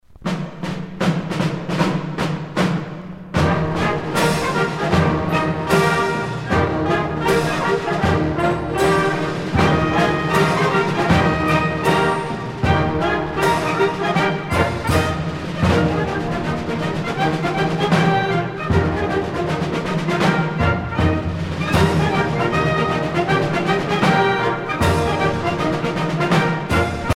circonstance : militaire
Pièce musicale éditée